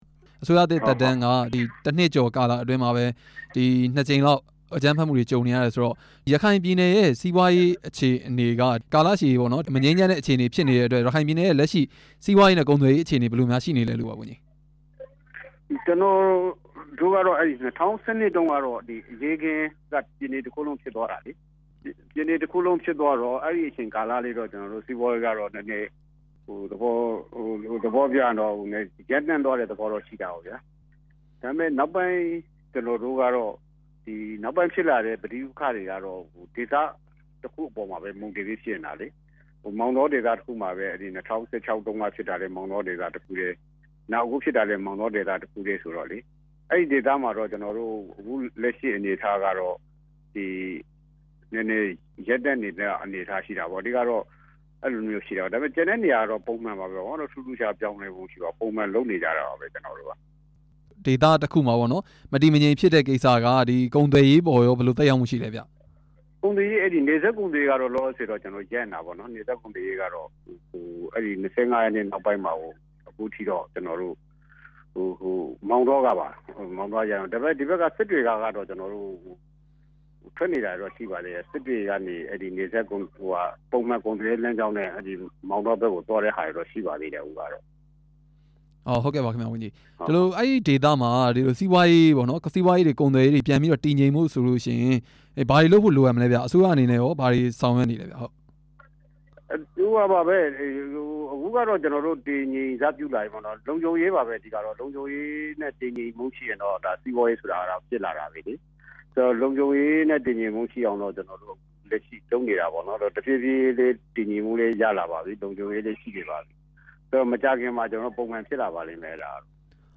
ရခိုင်ပြည်နယ် နယ်စပ်ကုန်သွယ်ရေး အခြေအနေ မေးမြန်းချက်